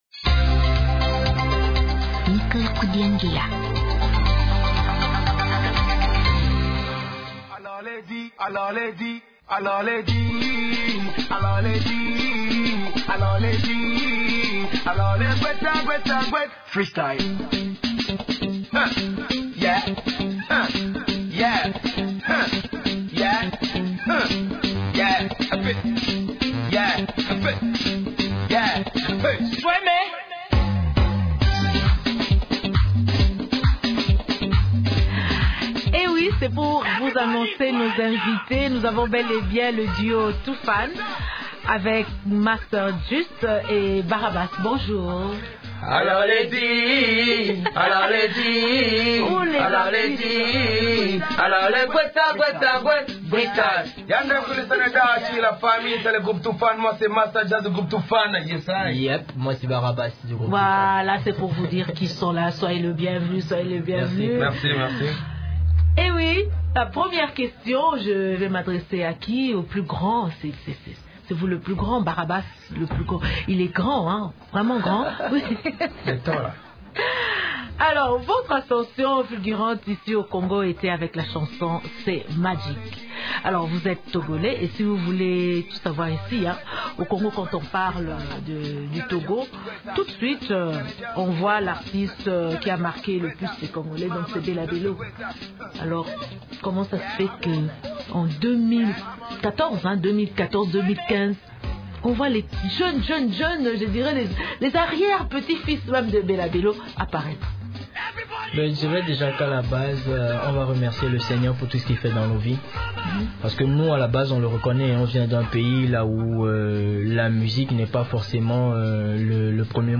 Les musiciens togolais du groupe «Toofan» affirment que la musique congolaise moderne a constitué une source d’inspiration pour l’Afrique de l’Ouest notamment pour le Togo. Ils l’ont reconnu, vendredi 5 juin, au cours de l’émission «Découverte » de Radio Okapi.